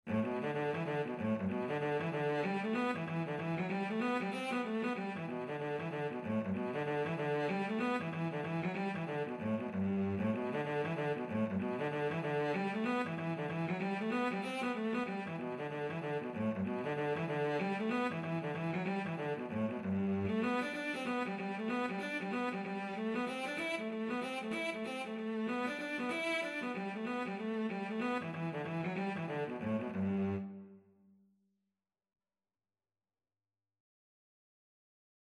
Cello version
G major (Sounding Pitch) (View more G major Music for Cello )
4/4 (View more 4/4 Music)
G3-E5
Cello  (View more Intermediate Cello Music)
Traditional (View more Traditional Cello Music)
Irish